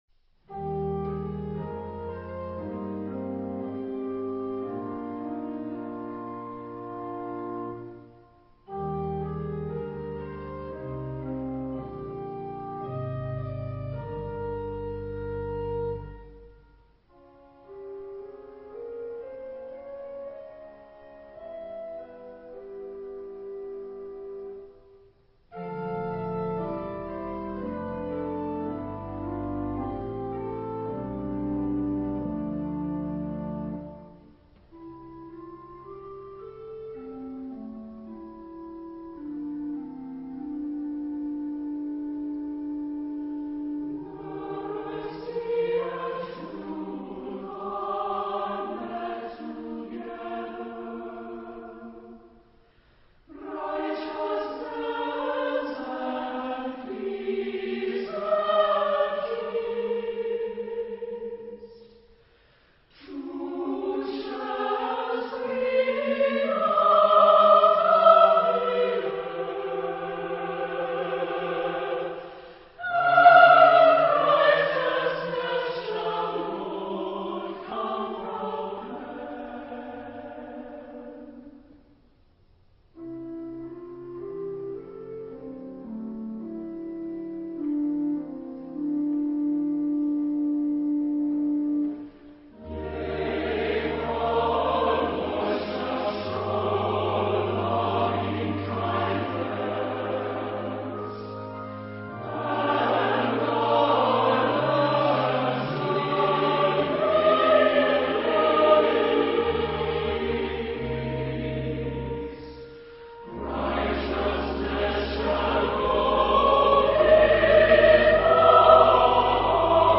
Genre-Style-Forme : Sacré ; Psaume ; Hymne (sacré)
Caractère de la pièce : tendre ; pieux
Type de choeur : SATB  (4 voix mixtes )
Instrumentation : Clavier  (1 partie(s) instrumentale(s))
Instruments : Orgue (1) ou Piano (1)